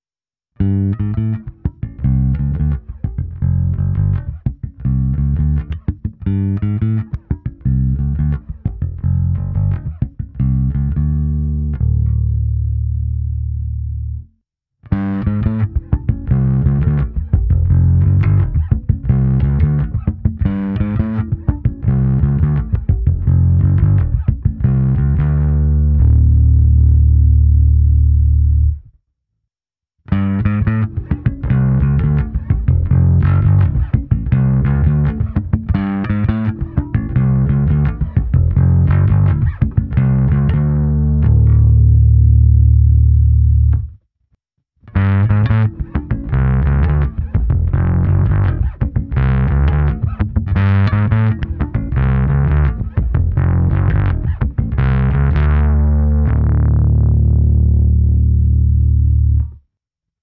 Oproti tomu režim STD (STANDARD) ponechává ve zvuku nezkreslené basy a zkresluje jen vyšší frekvence. A nakonec režim TUBESIM simuluje lampové zkreslení, funguje stejně jako režim STD, jen je k němu připojen simulátor elektronek.
Použitá baskytara je Fender American Professional II Precision Bass V s niklovými roundwound strunami Sadowsky Blue Label 40-125. Za zkreslovací krabičku jsem přidal preamp s kompresorem, základní ekvalizací a také se simulací aparátu. Ukázky jsou v pořadí: čistý zvuk přes preamp a pak vždy postupně režim TUBESIM, STD a nakonec FLAT.